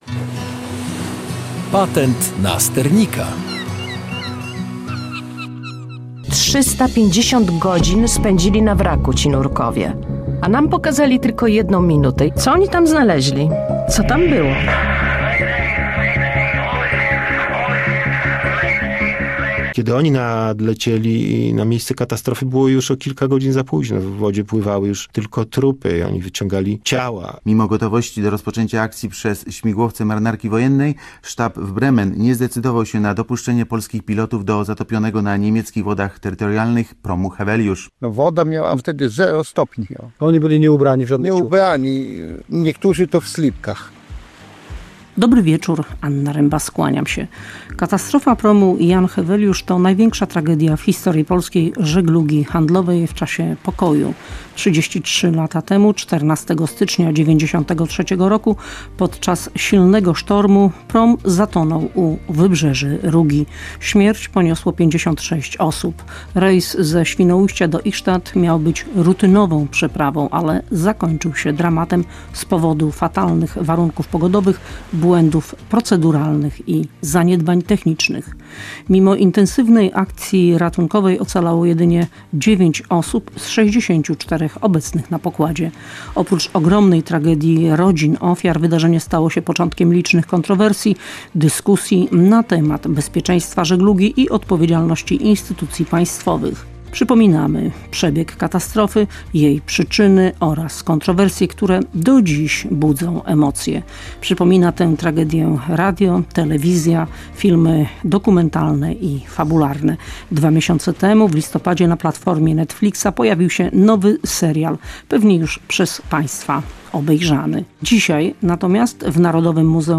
Dziennikarz dotarł do świadków, którzy przerwali milczenie w sprawie powodów katastrofy. Redaktor powiedział na antenie Programu 1 Polskiego Radia, dlaczego niektóre osoby dopiero po ponad trzydziestu latach zdecydowały się ujawnić prawdę.